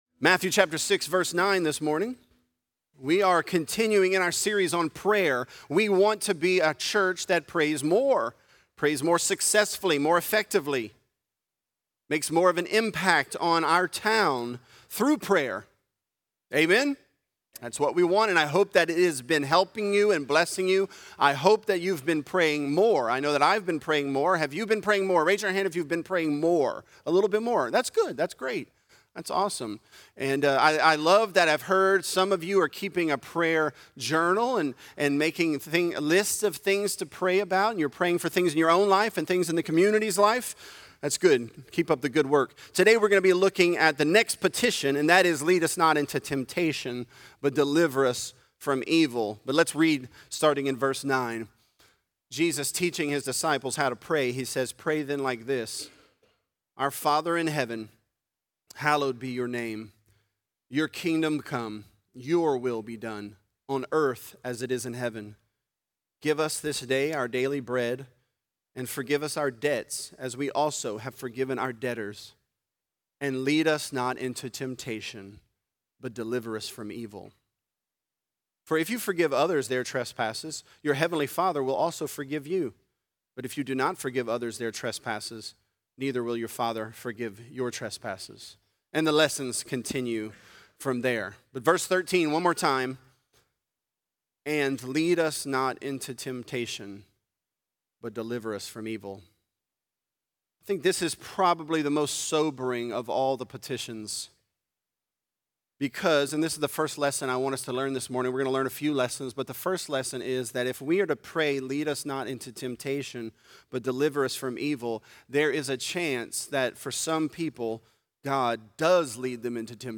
This is a part of our sermon series, "Teach Us To Pray."Christ Church Lafayette